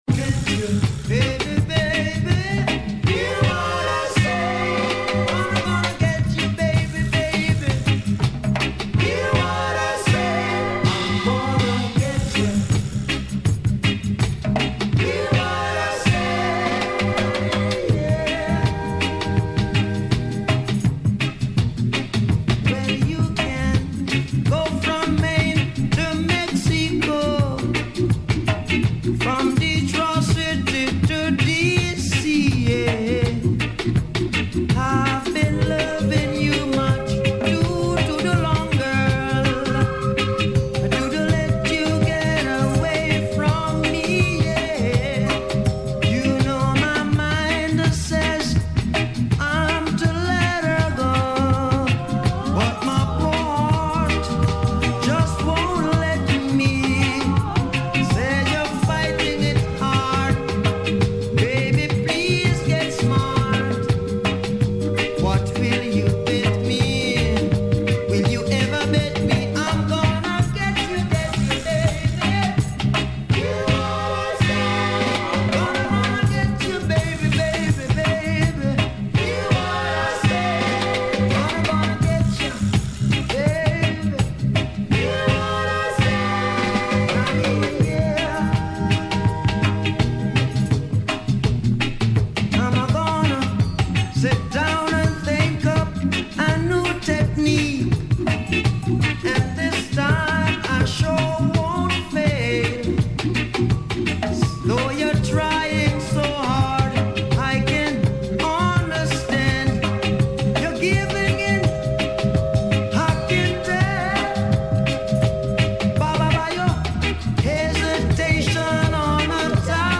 Some technical problems in tired mood.